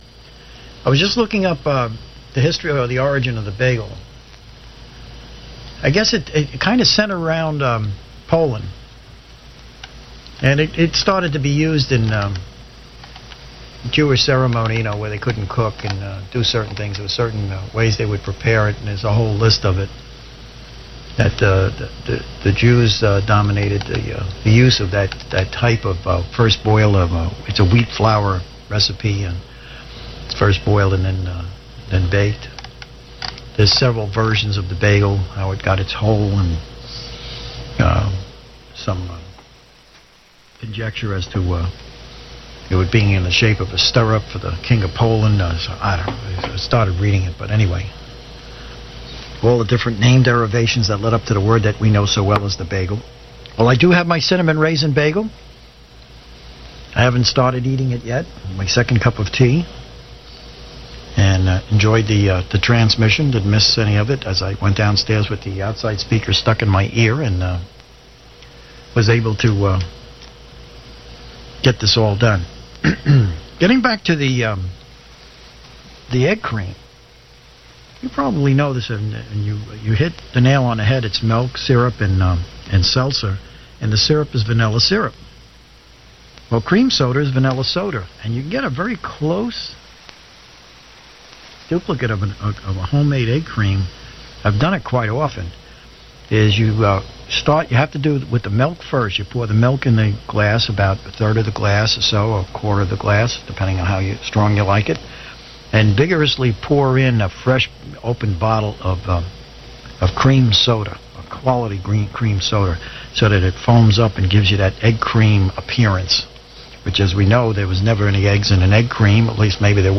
The 75 Meter AM Audio Files